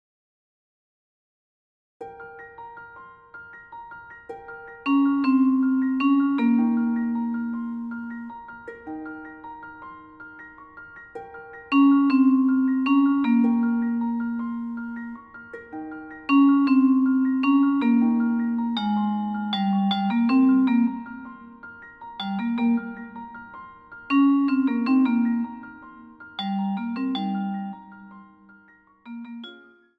Instrumental Solos Saxophone
Demo